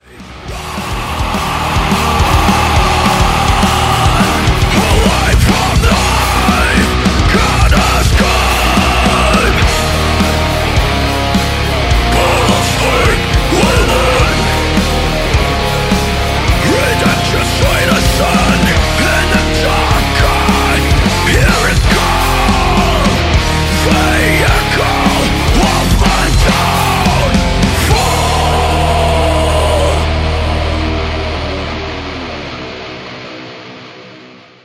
a relentless track
With crushing guitars, haunting atmospheres